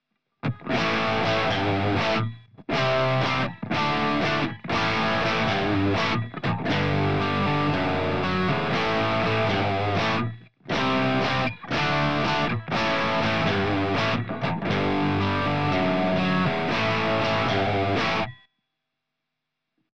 Die Duesenberg bereitet uns die Basis für die rechte Seite.
Mit Absicht haben wir diese Gitarre etwas cleaner klingen lassen.
Auf der rechten Seite ist der Klang heller geraten, das soll unsere Leadgitarre werden.
gitarrensound_fetter_machen_endergebnis_rechts.mp3